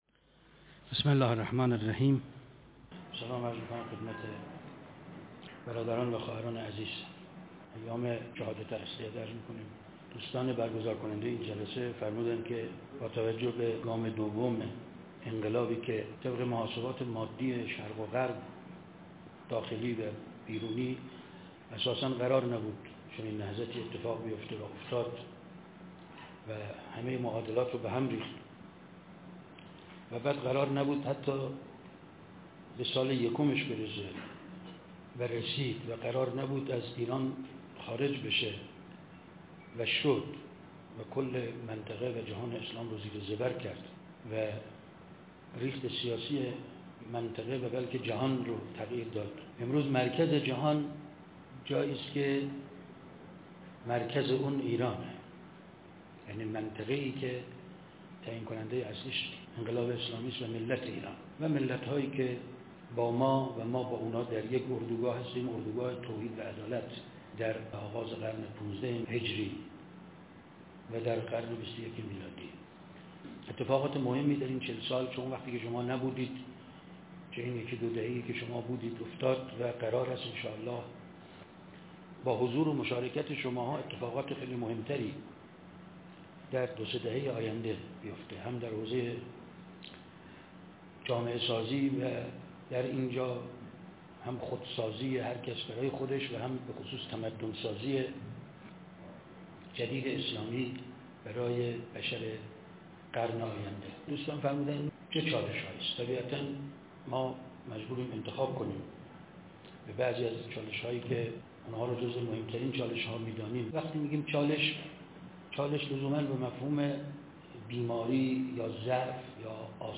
شهادت امام علی الرضا ع _ دانشجویان استان البرز _ مشهد _ ۱۳۹۸